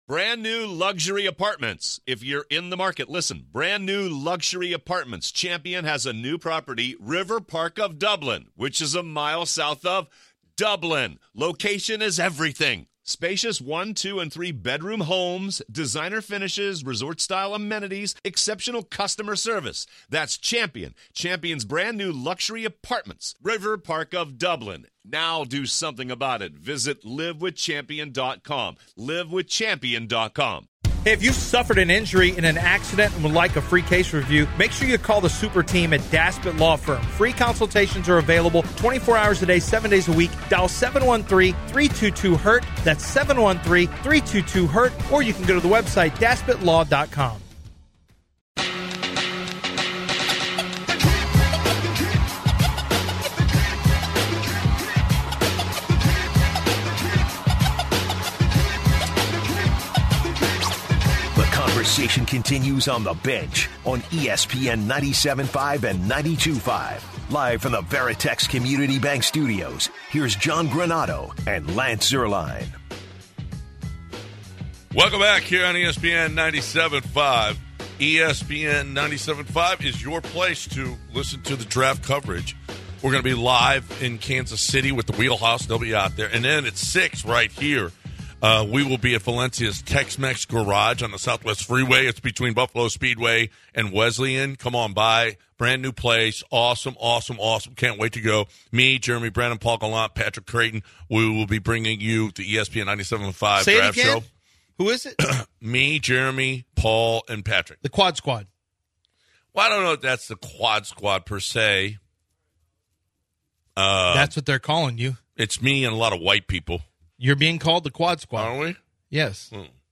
In our second hour, we're joined by Professional Golfer David Duval who gives us his thoughts on this weekends tournament in Houston. Also, we're joined by Jeff Van Gundy of ESPN to get his feelings on the Houston Rockets hiring of Ime Udoka. Also, we hear from the new Rockets head coach about what his philosophies are and what he expects from his players.